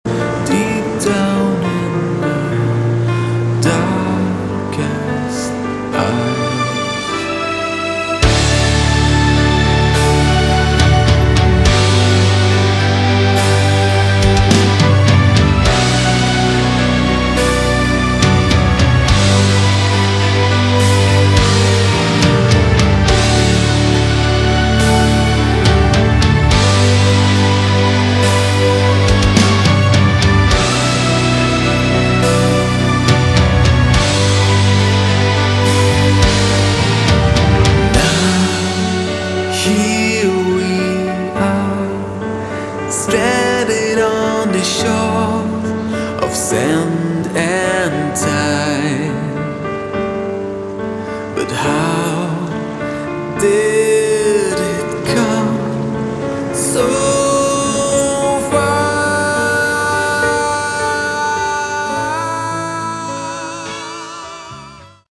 Category: Prog Rock/Metal
vocals, additional guitars
keyboards
guitars
bass
drums